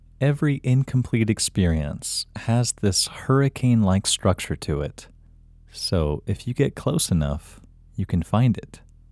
IN – Second Way – English Male 7